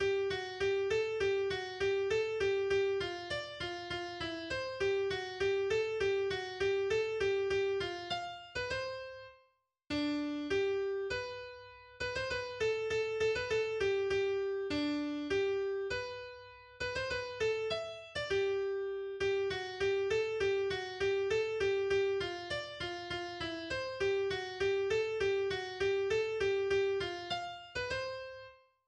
schwäbisches Volkslied